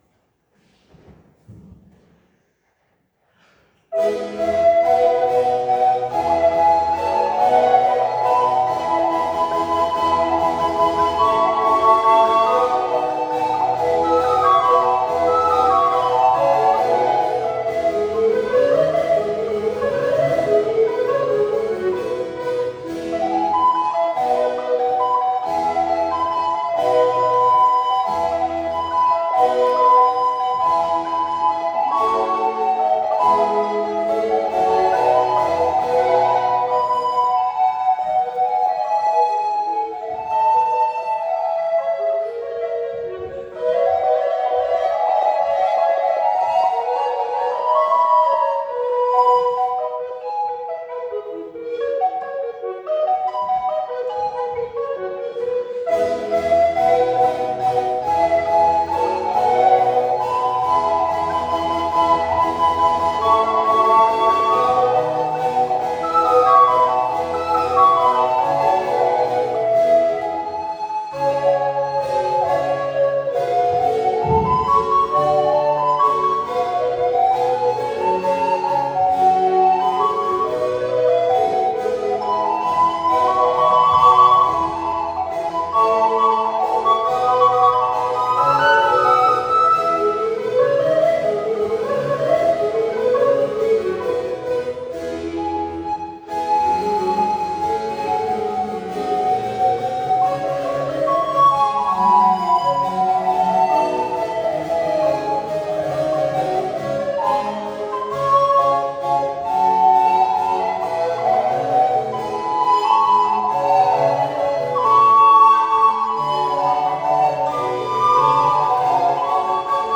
August 2025 ein Konzert der besonderen Art mit dem Blockflöten Ensemble BlockArt statt:
Blockflöten und Gesang
Blockflöten
Cembalo und Orgel
Blockflötenkonzert Kirche Obermühlbach
Zahlreiche Gäste verfolgten im Kirchenraum, mit seiner ausgezeichneten Akkustik, den abwechslungsreichen musikalischen und vokalen Darbietungen der Musiker.
zugabe.wav